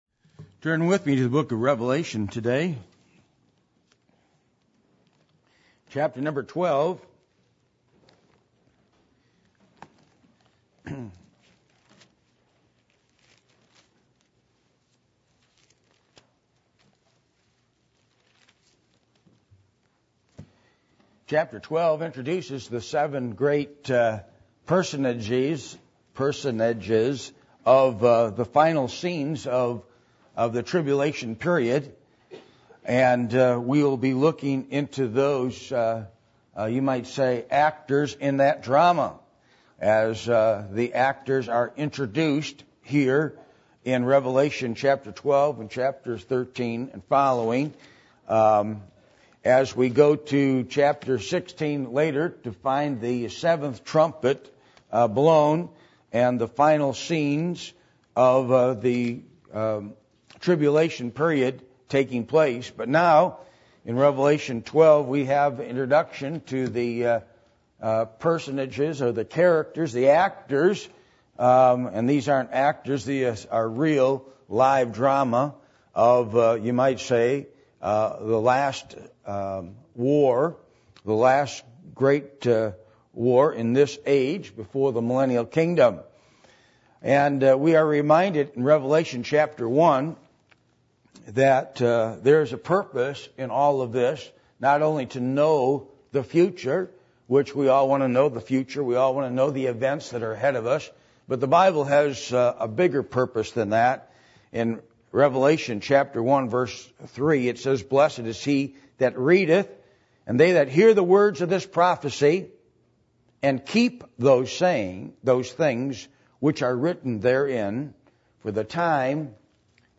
Passage: Revelation 12:1-17 Service Type: Sunday Morning